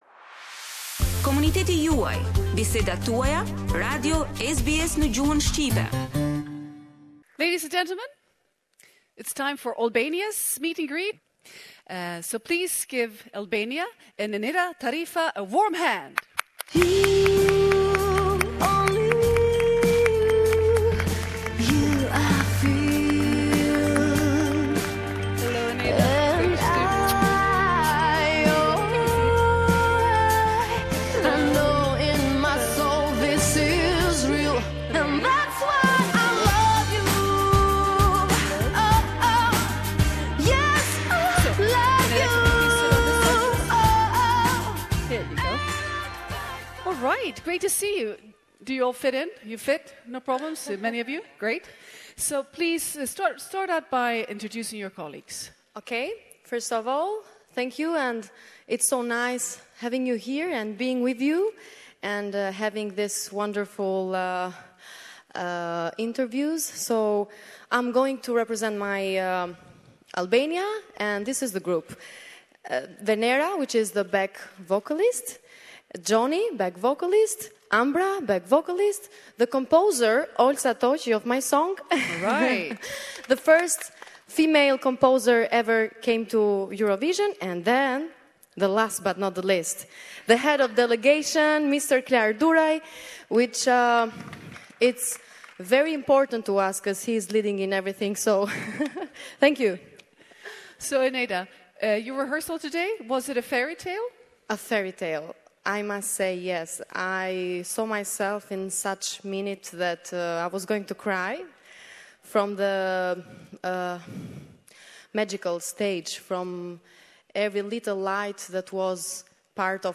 Press Conference of the Albanian representative at the Eurovision Song Contest.